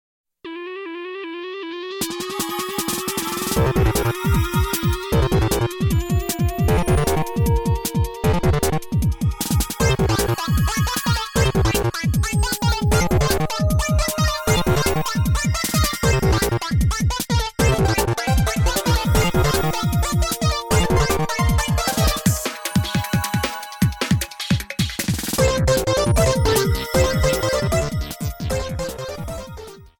Remake
applied fade-out